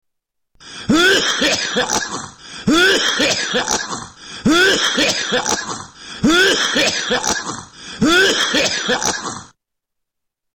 Hack Cough